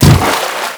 fs_water_colo3.wav